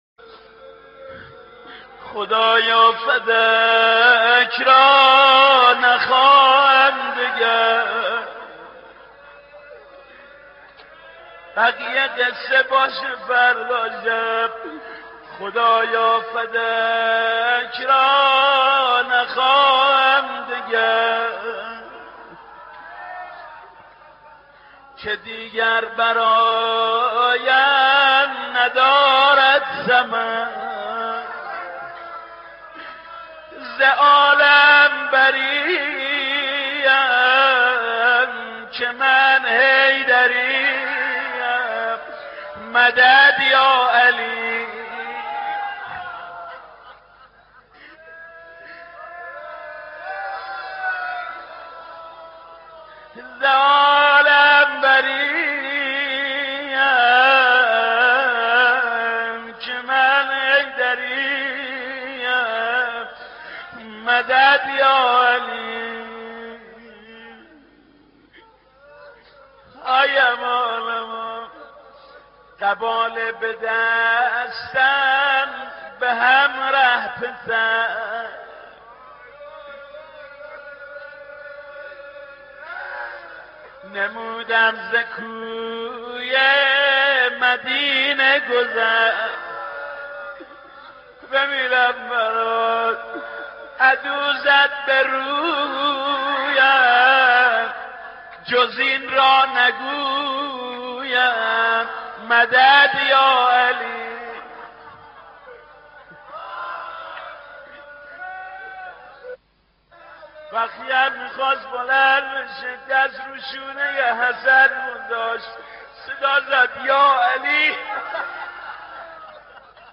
مداحی و نوحه
نوحه خوانی شهادت حضرت فاطمه زهرا(س